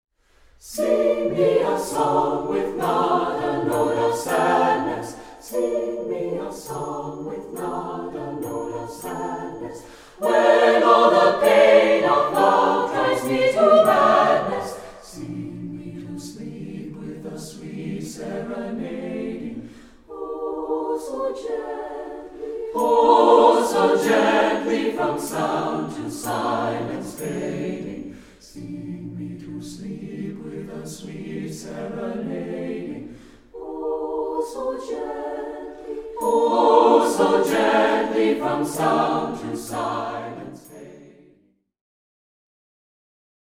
Voicing: SSAB